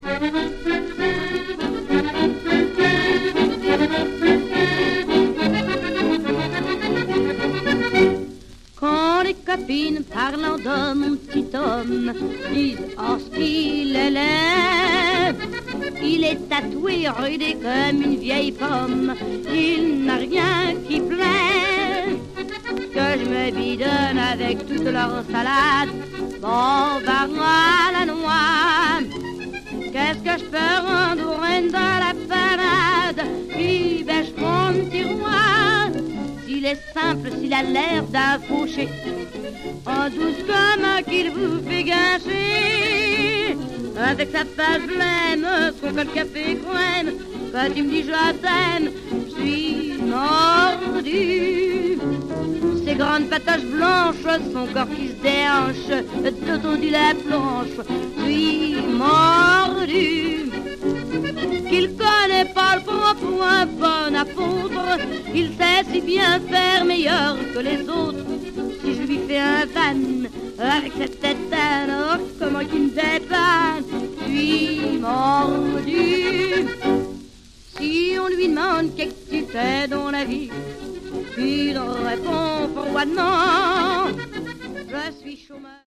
4 track mono EP by France’s greatest chanteuse.